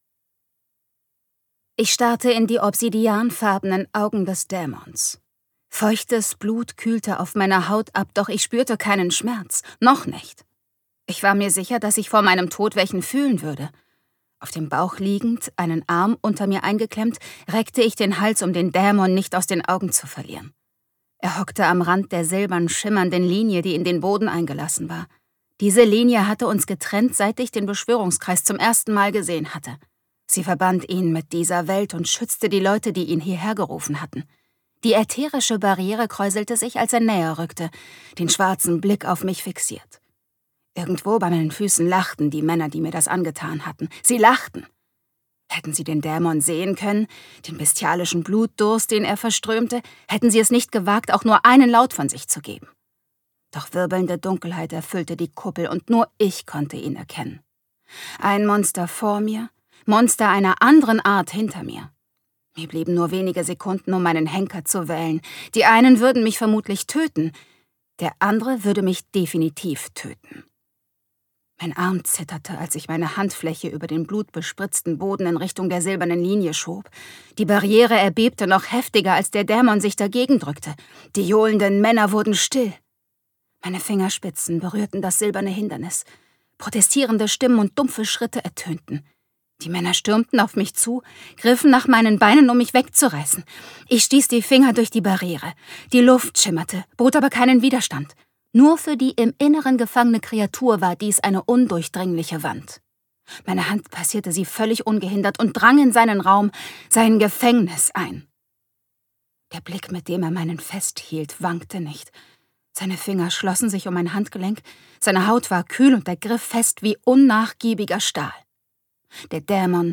Ein Cookie für den Dämon - Annette Marie | argon hörbuch
Gekürzt Autorisierte, d.h. von Autor:innen und / oder Verlagen freigegebene, bearbeitete Fassung.